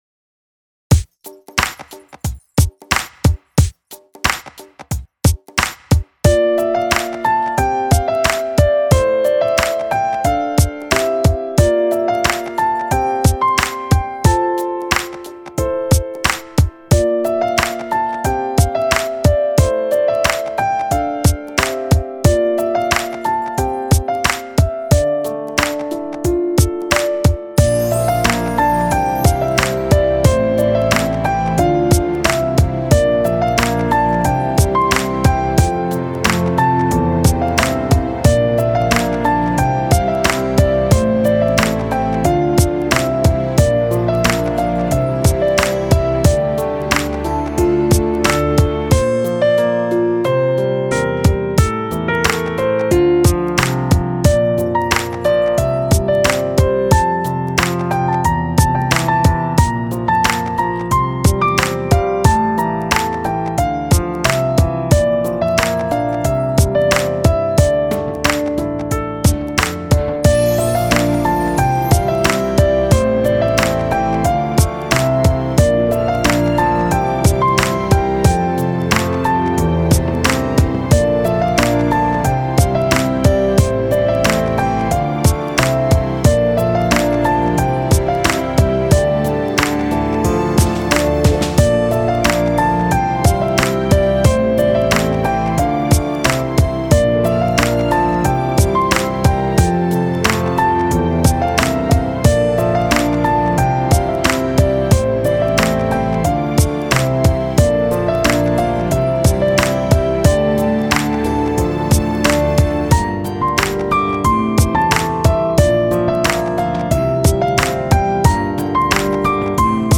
（为你而演绎的旋律/钢琴） 激动社区，陪你一起慢慢变老！